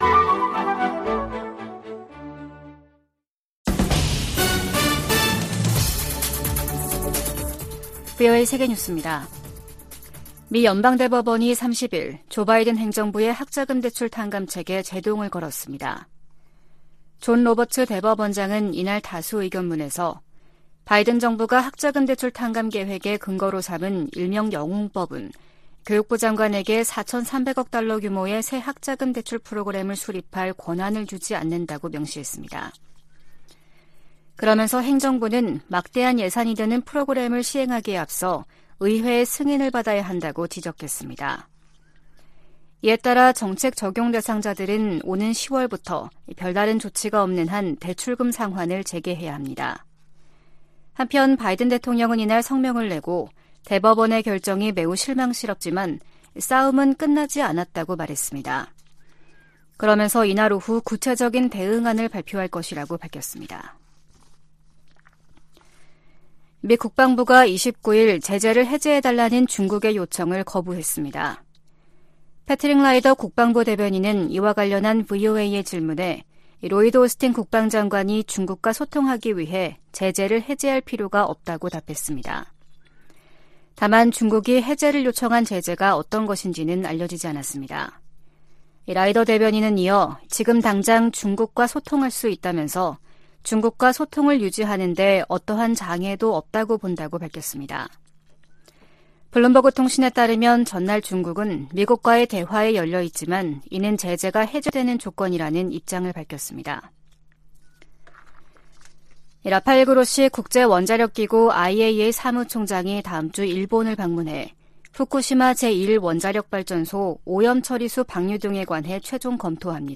VOA 한국어 아침 뉴스 프로그램 '워싱턴 뉴스 광장' 2023년 7월 1일 방송입니다. 김영호 한국 통일부 장관 후보자는 통일부 역할이 변해야 한다며, 북한 인권을 보편적 관점에서 접근해야 한다고 말했습니다. 유엔화상 회의에서 미국은 북한에 모든 납북자들을 송환해야 한다고 촉구했습니다. 핵 탑재 가능한 미국 오하이오급 잠수함이 머지않아 한국에 기항할 것이라고 미 국방부 대변인이 밝혔습니다.